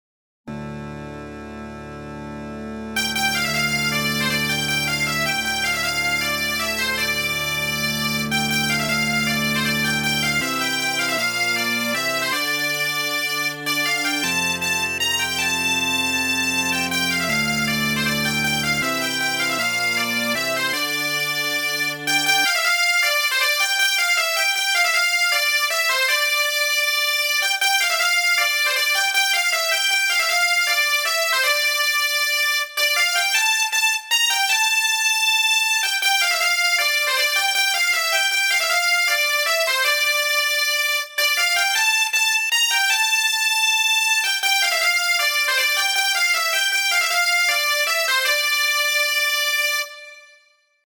Как практичный аранжировщик - взял бы что-то приблизительно похожее: Вложения Bpipes.mp3 Bpipes.mp3 1,6 MB · Просмотры: 1.130